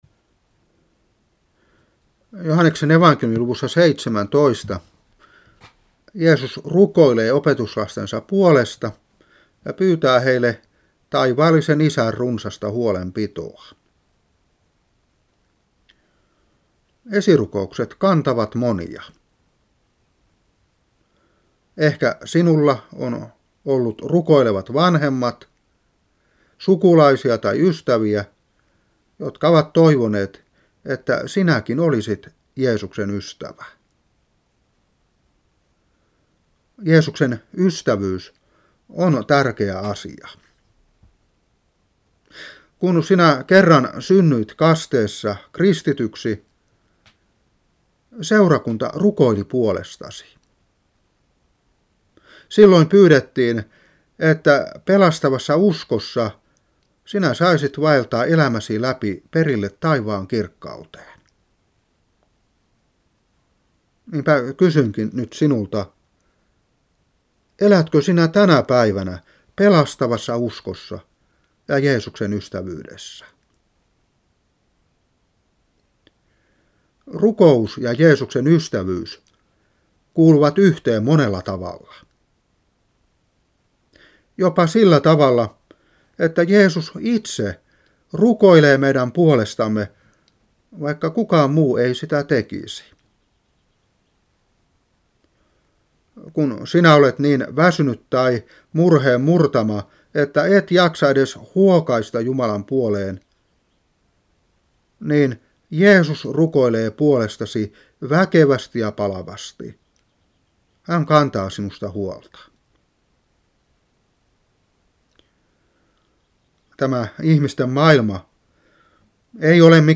Saarna 1994-5.